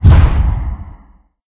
anomaly_gravy_hit1.ogg